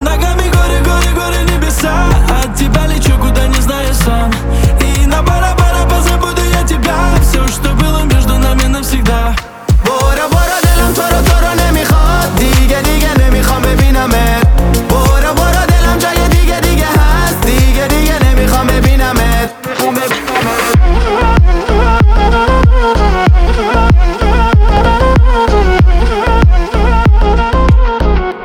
поп
ремиксы